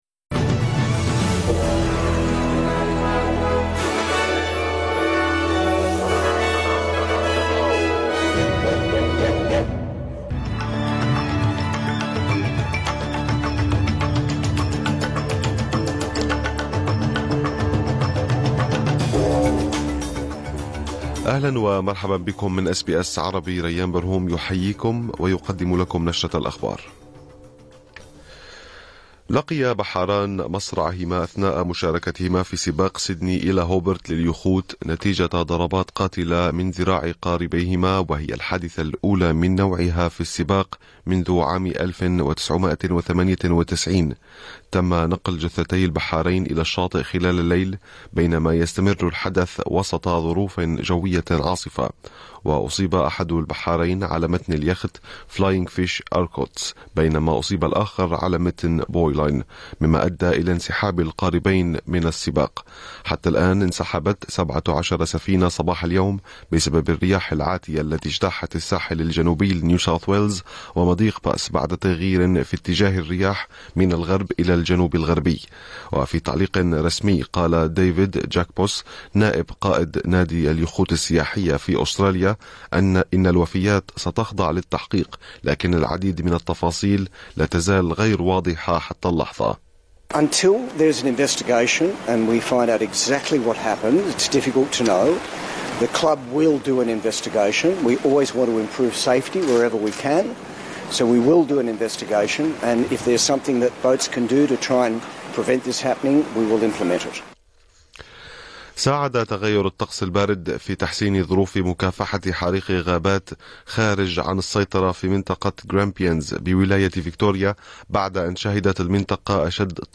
نشرة أخبار الظهيرة 27/12/2024